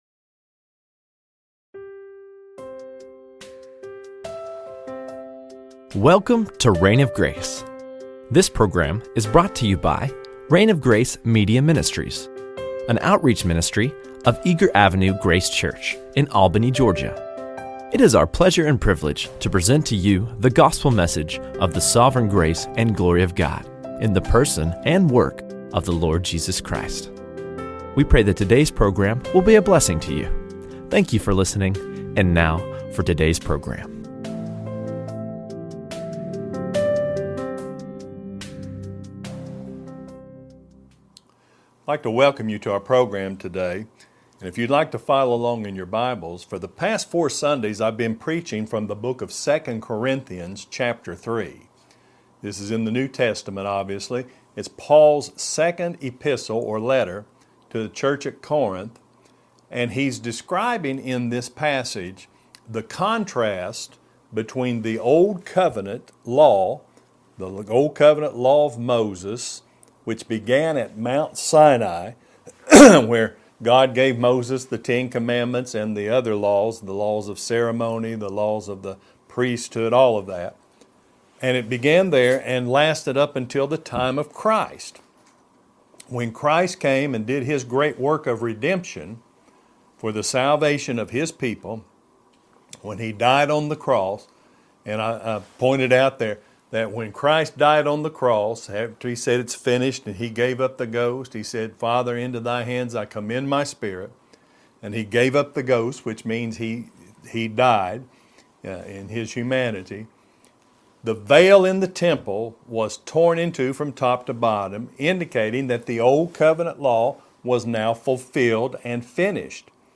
The New Testament - 5 | SermonAudio Broadcaster is Live View the Live Stream Share this sermon Disabled by adblocker Copy URL Copied!